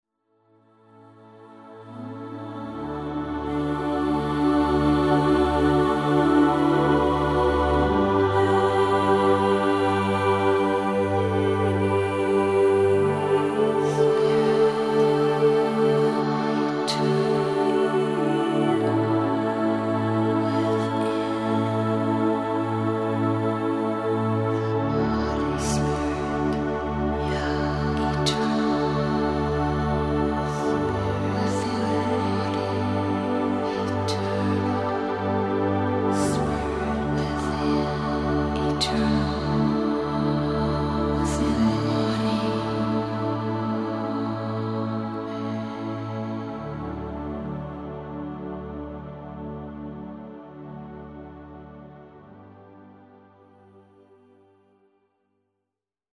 Brain Balancing Music for your deepest well being
delicately spiritual music and vocals